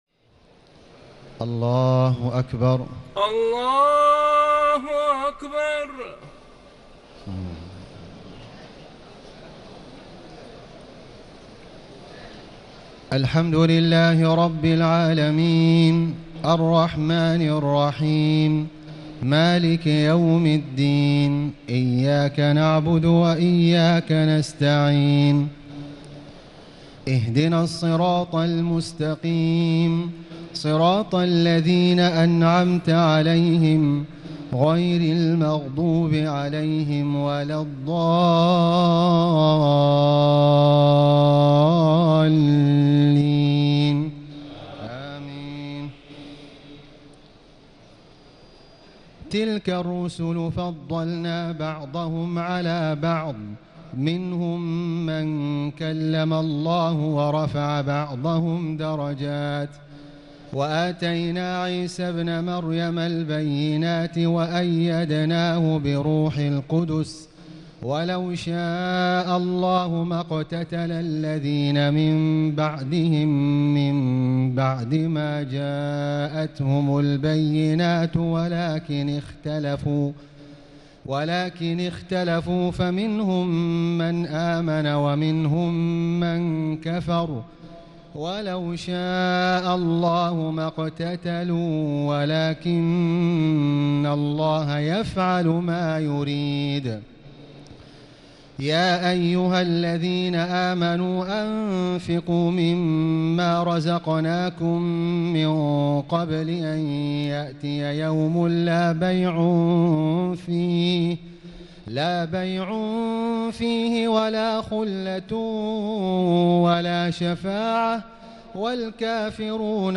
تهجد ليلة 23 رمضان 1439هـ من سورتي البقرة (253-286) و آل عمران (1-32) Tahajjud 23 st night Ramadan 1439H from Surah Al-Baqara and Aal-i-Imraan > تراويح الحرم المكي عام 1439 🕋 > التراويح - تلاوات الحرمين